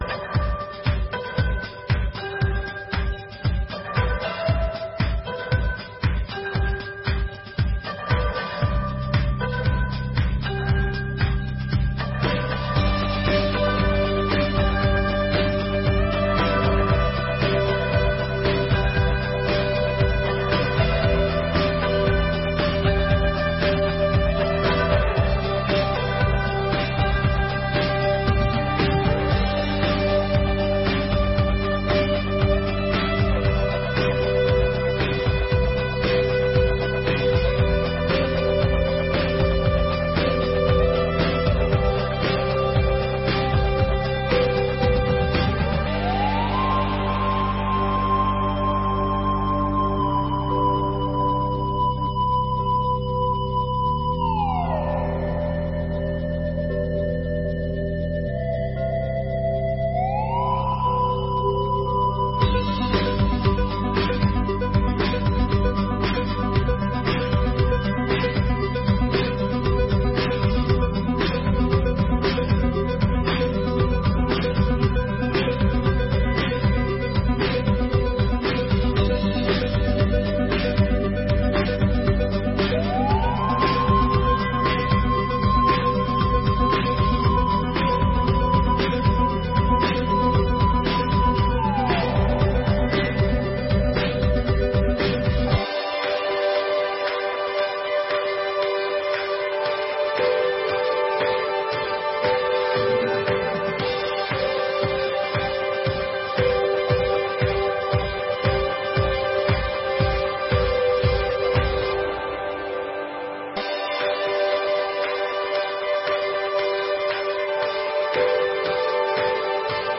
Audiência Pública: Discussão sobre a reforma da previdência municipal.
audiencia-publica-discussao-sobre-a-reforma-da-previdencia-municipal